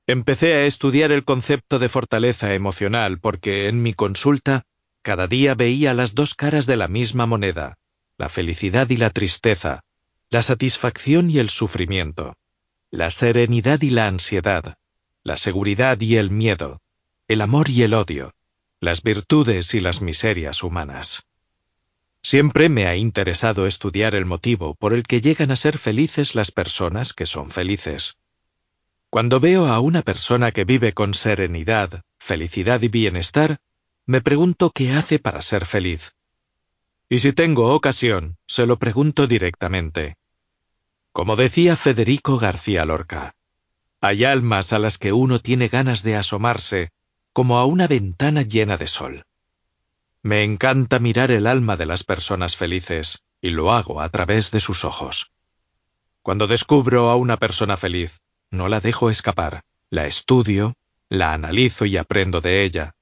audiolibro Fortaleza emocional Toma distancia piensa diferente y atrevete a actuar para adaptarte a los cambios Tomas Navarro